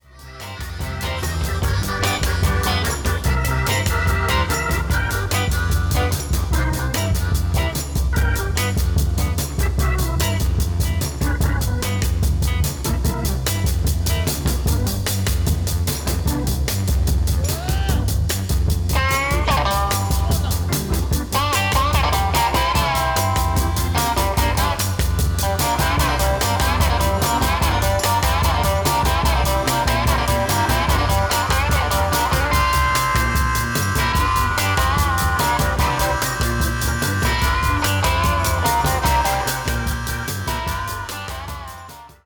• stereo on: